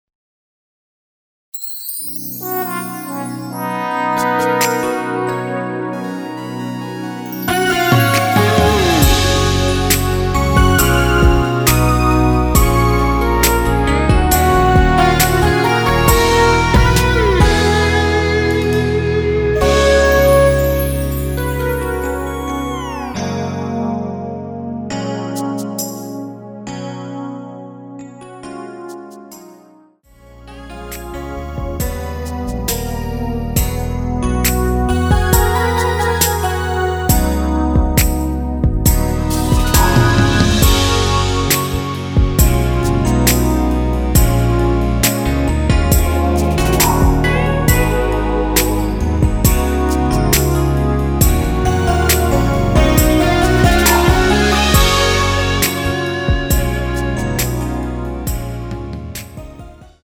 원키에서(-1)내린(짧은편곡) MR입니다.
F#
앞부분30초, 뒷부분30초씩 편집해서 올려 드리고 있습니다.
중간에 음이 끈어지고 다시 나오는 이유는